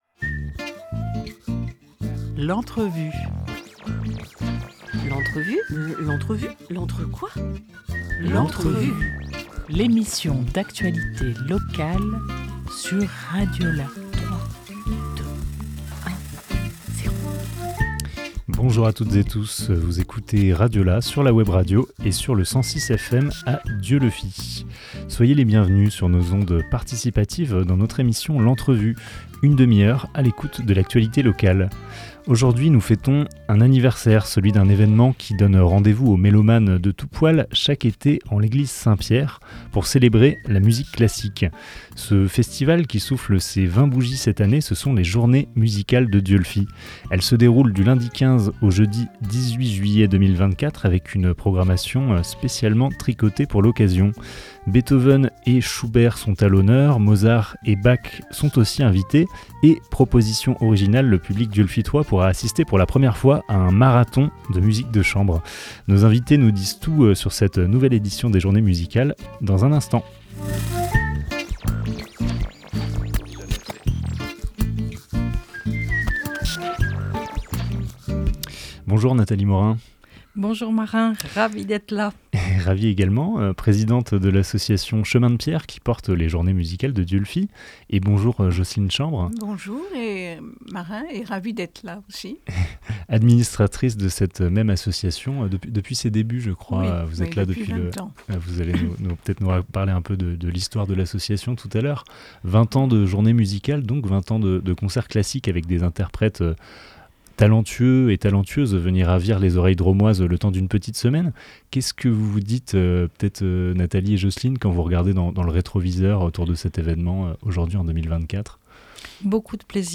9 juillet 2024 11:02 | Interview